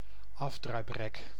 Ääntäminen
Synonyymit ive if commun Ääntäminen France: IPA: [if] Paris Haettu sana löytyi näillä lähdekielillä: ranska Käännös Ääninäyte Substantiivit 1. venijnboom 2. ijf 3. taxus 4. afdruiprek Suku: m .